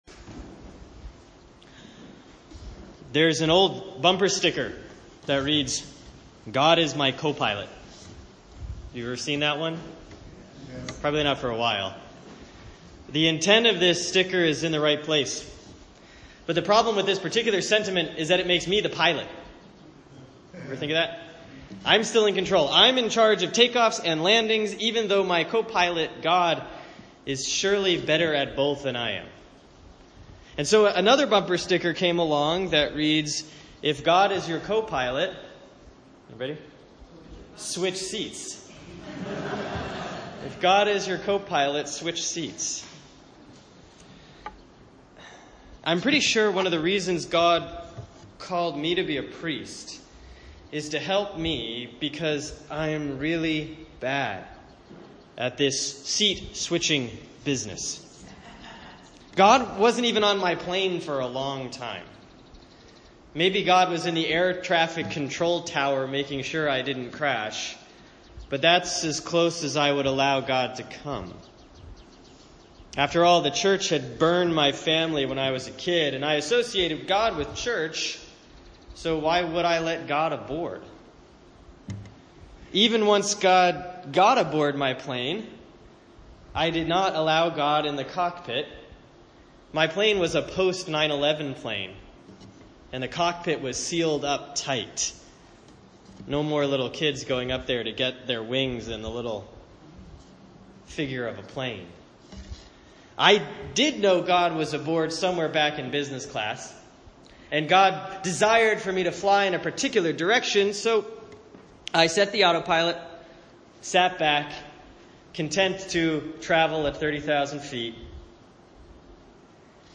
Sermon for Sunday, July 9, 2017 || Proper 9A || Matthew 11:16-19, 25-30